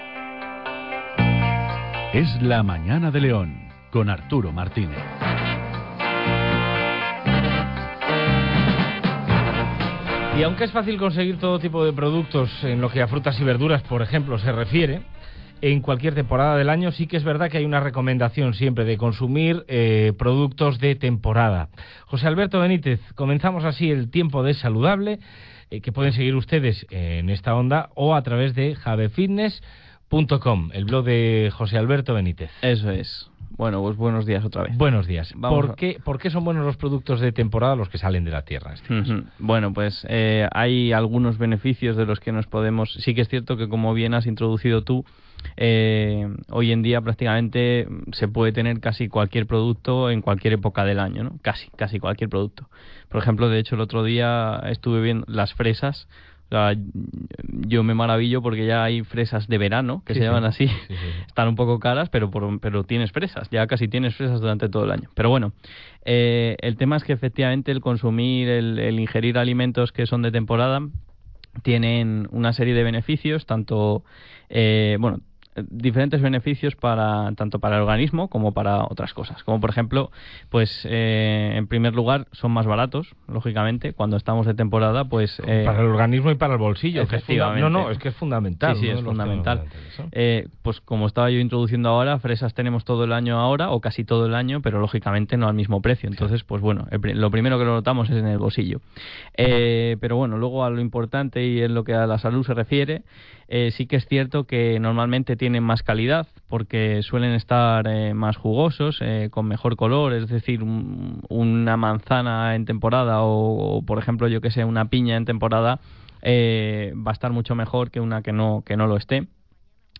Hoy os traigo el cuadragésimo noveno programa de la sección que comenzamos en la radio local hace unos meses y que hemos denominado Es Saludable.